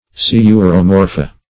Sciuromorpha \Sci`u*ro*mor"pha\, n. pl.